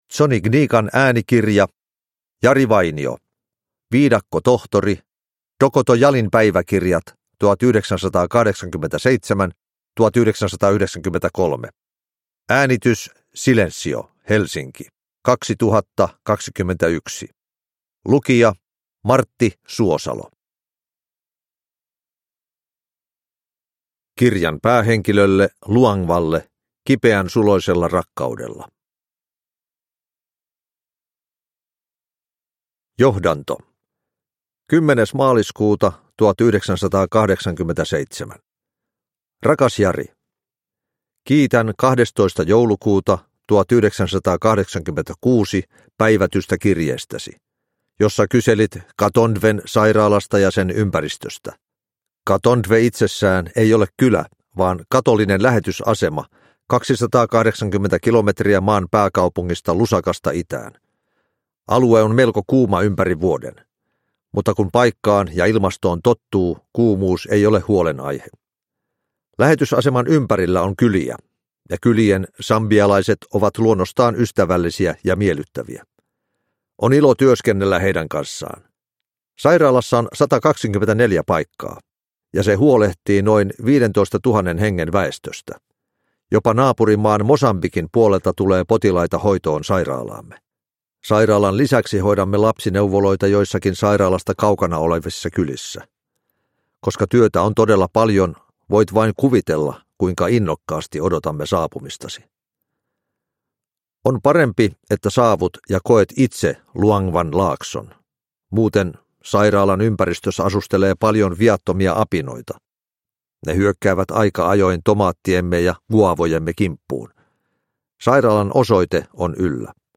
Viidakkotohtori – Ljudbok – Laddas ner
Uppläsare: Martti Suosalo